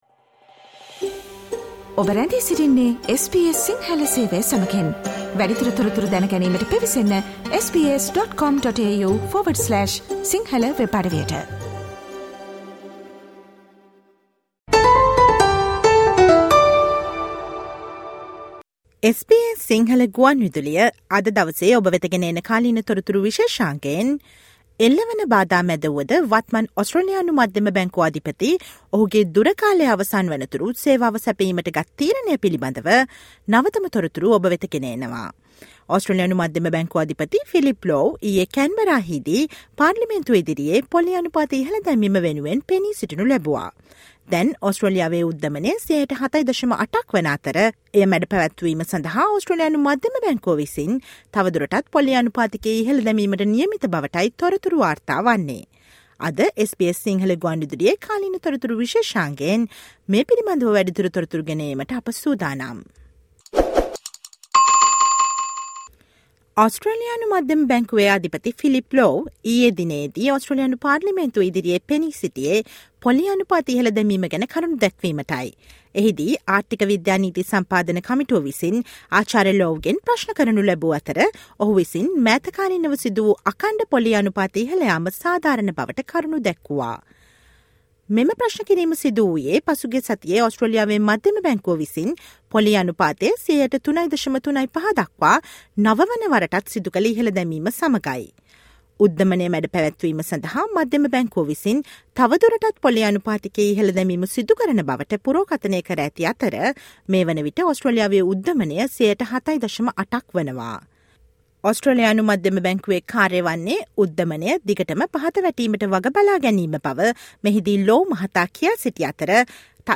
ඔස්ට්‍රේලියානු මධ්‍යම බැංකු අධිපති ෆිලිප් ලෝව් පසුගිය බදාදා කැන්බරා හිදී පාර්ලිමේන්තුව ඉදිරියේ පොලී අනුපාත ඉහළ දැමීම සාධාරණ බවට කරුණු ඉදිරිපත් කිරීම සහ ඉදිරියේදීත් වැඩි වීමට නියමිත පොලී අනුපාත ගැන පලවූ අනාවැකි පිළිබඳ නවතම තොරතුරු දැන ගන්න සවන් දෙන්න අද SBS සිංහල ගුවන් විදුලි වැඩසටහනේ කාලීන තොරතුරු විශේෂාංගයට.